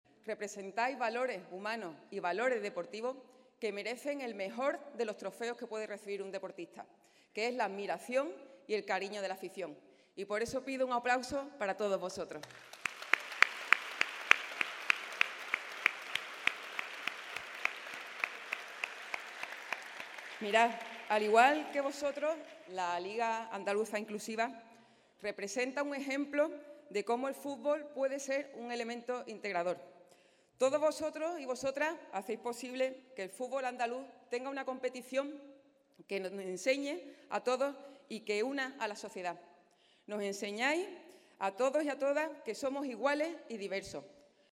En torno a 400 futbolistas de 23 equipos de la provincia se han dado cita esta tarde en el Teatro Villamarta de Jerez para celebrar la I Gala de la Liga Andaluza de Fútbol Inclusivo en Cádiz.
Almudena-Martinez-en-gala-del-futbol-inclusivo.mp3